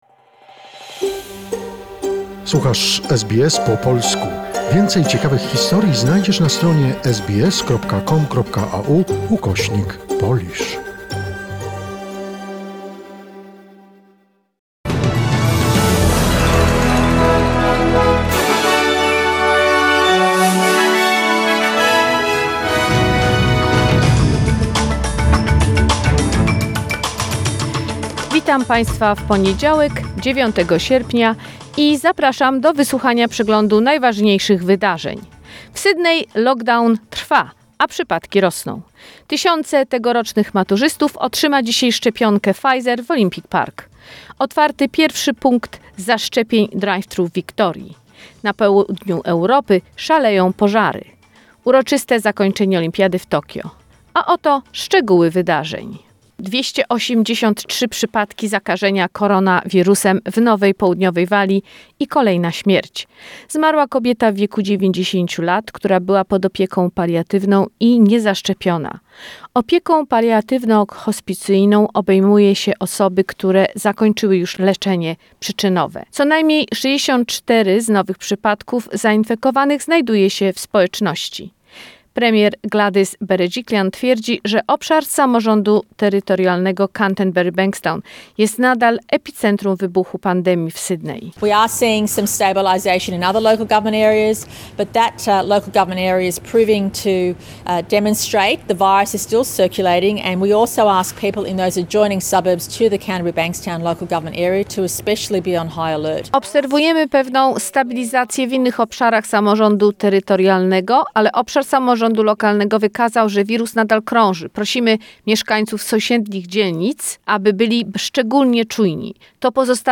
SBS News in Polish, 9 August 2021